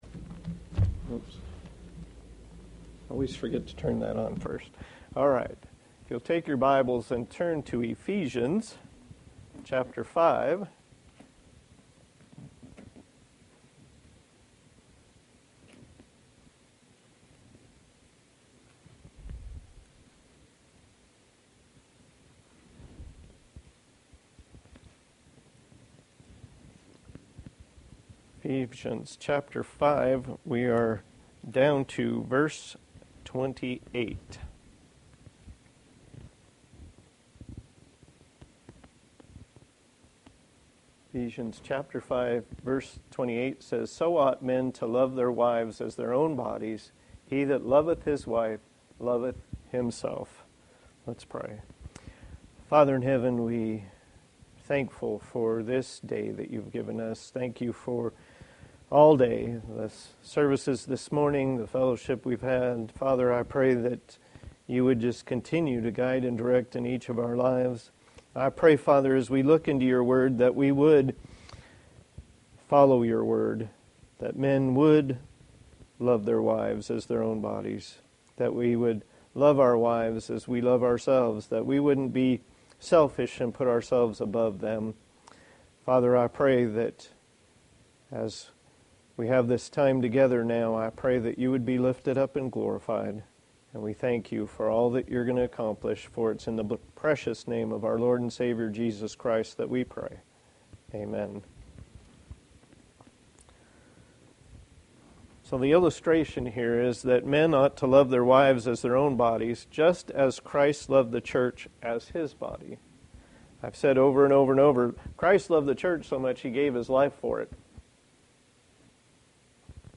Passage: Ephesians 5:28 Service Type: Sunday Evening